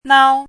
chinese-voice - 汉字语音库
nao1.mp3